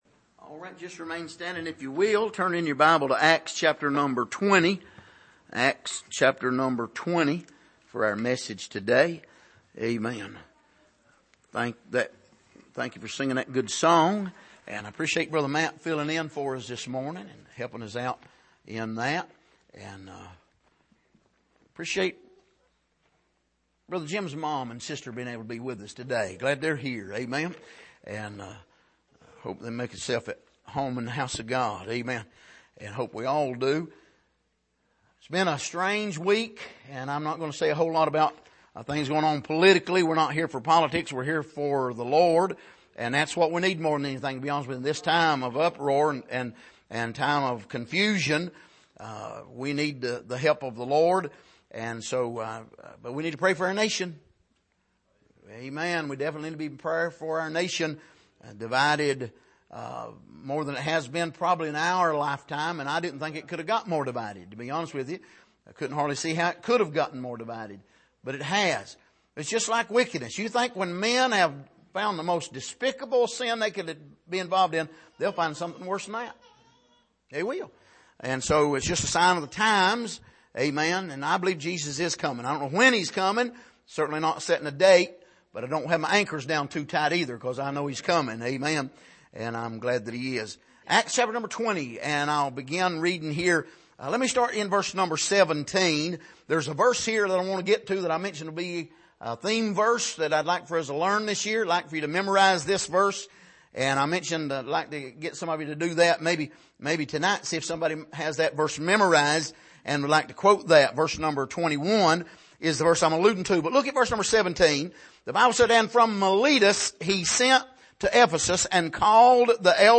Passage: Acts 20:17-38 Service: Sunday Morning The Wolves Are Howling and Prowling « Who Are You In Luke 22?